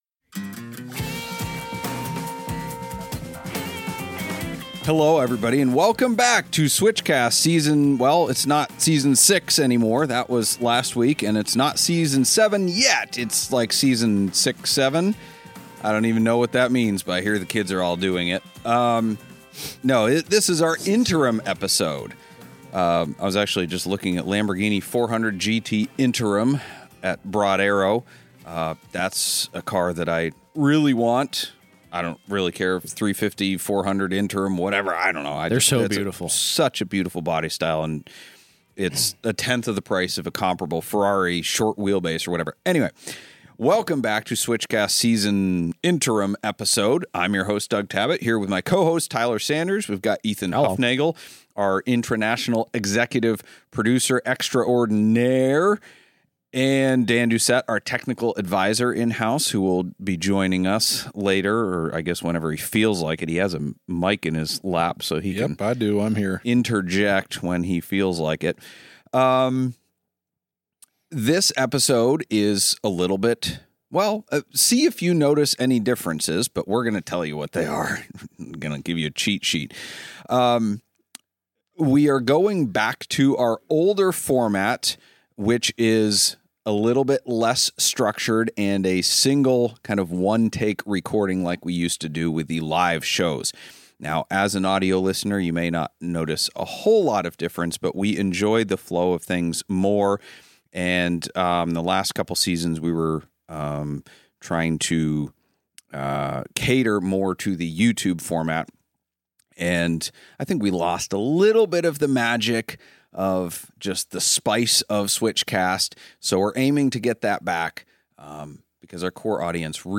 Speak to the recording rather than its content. We're back to our old format of one giant, longer episode recorded in one take!